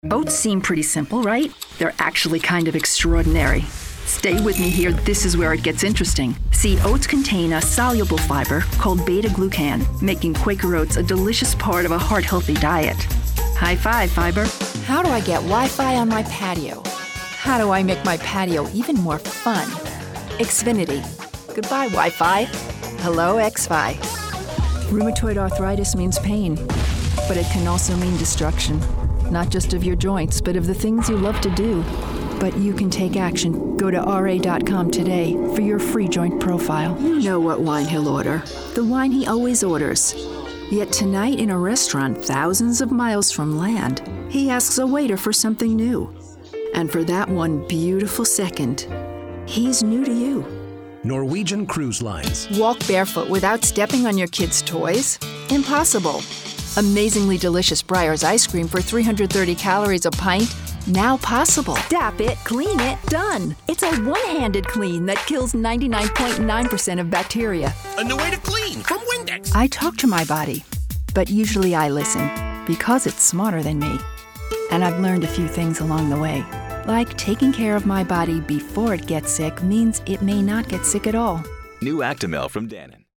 Adult, Mature Adult
• Broadcast quality home booth with Source-Connect Standard
new york | character
standard us | natural
COMMERCIAL 💸